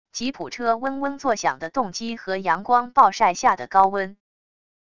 吉普车嗡嗡作响的动机和阳光暴晒下的高温wav音频